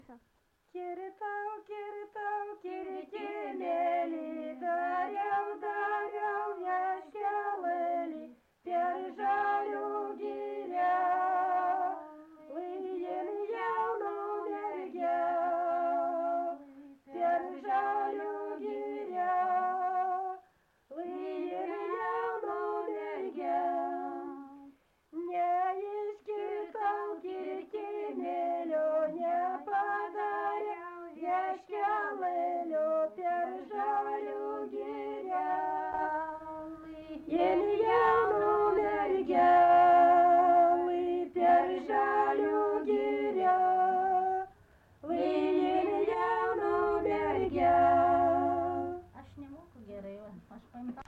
Dalykas, tema daina
Erdvinė aprėptis Mitriškės
Atlikimo pubūdis vokalinis